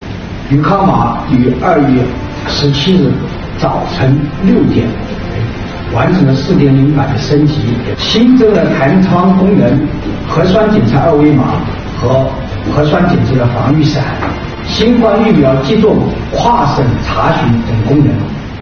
今（16）日，重庆市政府新闻办举行渝康码4.0系统升级新闻发布会，介绍相关情况。